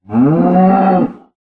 mob / cow1